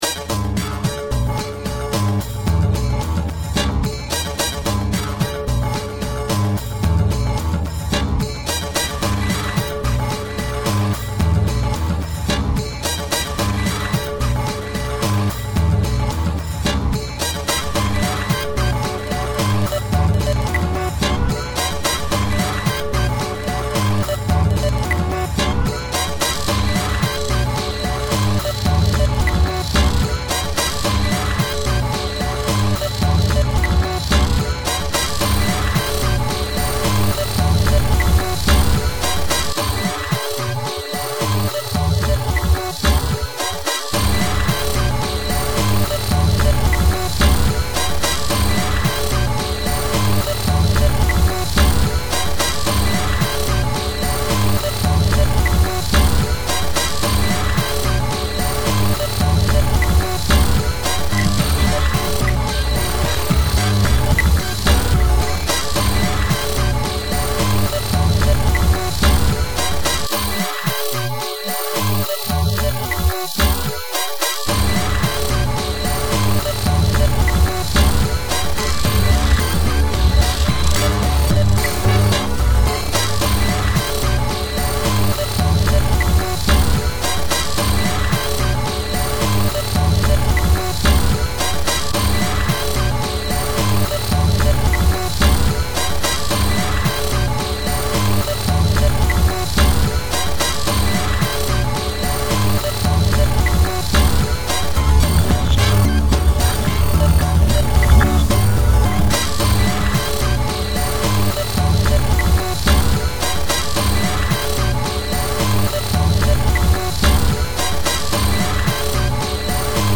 An incessant-breakbeat-meets-stadium-rock Double A-Side
is a chaotic beat-soaked mixture.